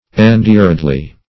endearedly - definition of endearedly - synonyms, pronunciation, spelling from Free Dictionary Search Result for " endearedly" : The Collaborative International Dictionary of English v.0.48: Endearedly \En*dear"ed*ly\, adv.